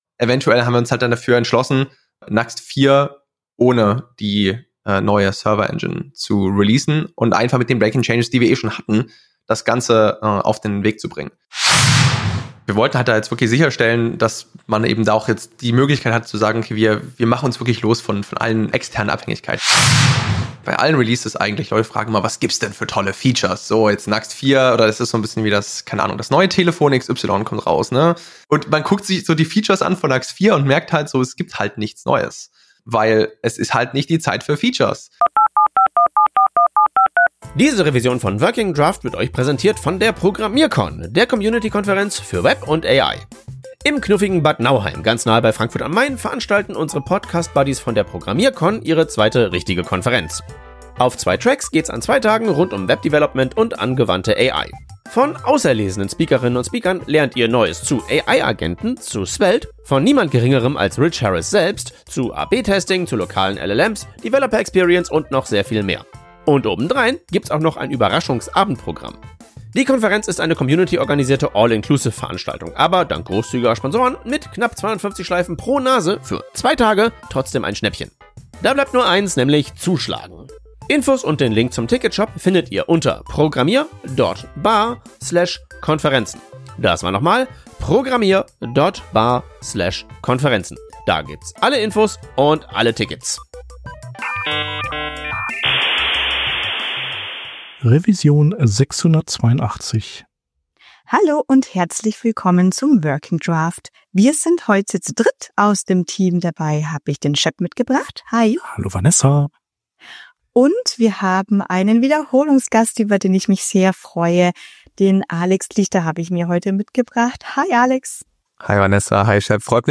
Wöchentlicher Podcast für Frontend Devs, Design Engineers und Web-Entwickler:innen